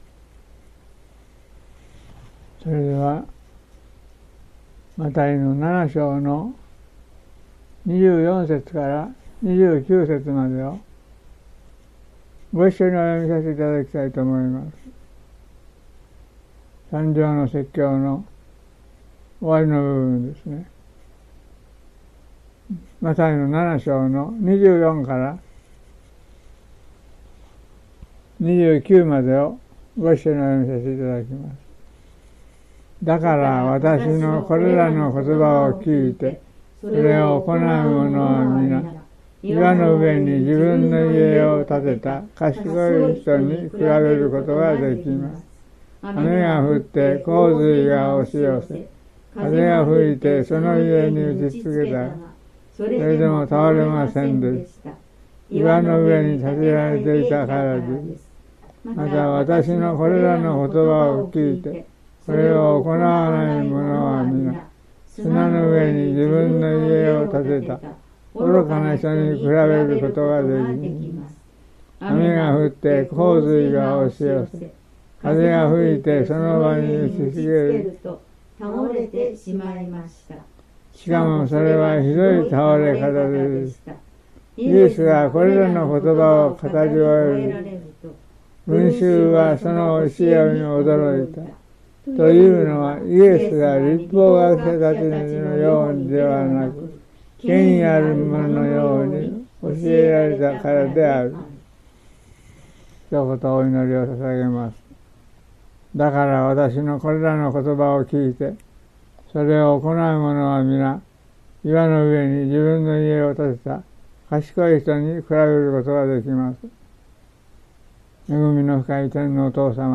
音声： 新年礼拝2026 「岩の上に」
【礼拝メッセージ】（一部分）